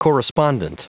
Prononciation du mot correspondent en anglais (fichier audio)
Prononciation du mot : correspondent